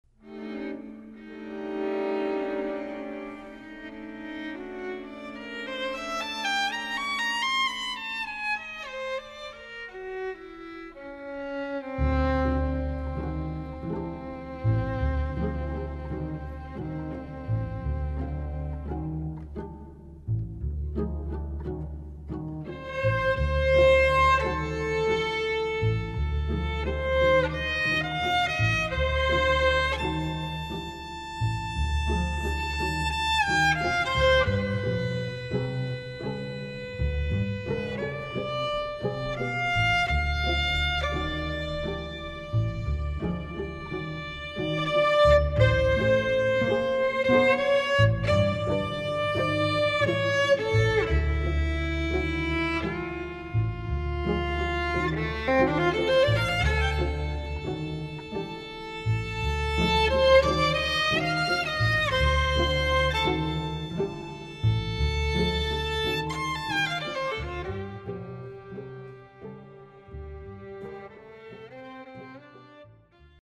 Please note: These samples are not of CD quality.
Trio for Violin, Viola and Bass